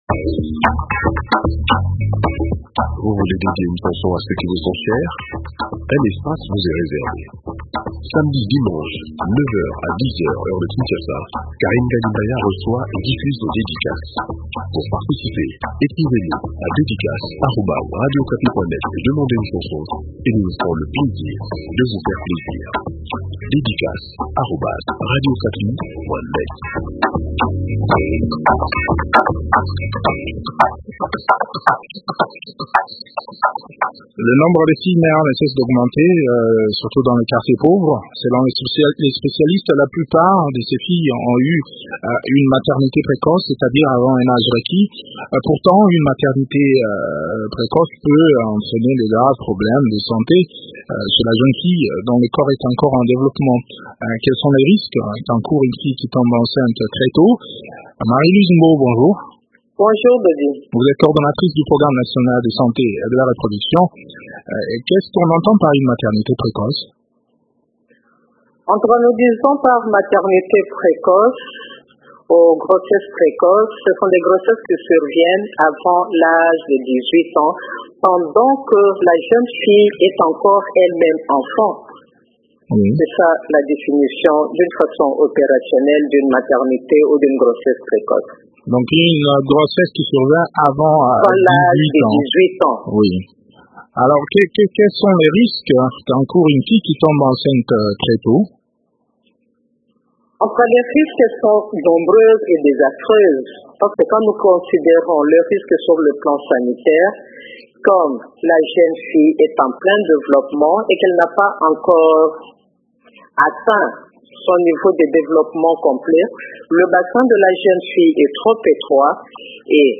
Le point sur les autres risques liés à l’accouchement avant dix huit ans dans cet entretien